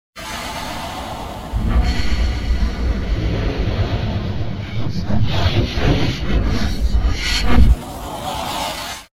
Root > sounds > weapons > hero > oracle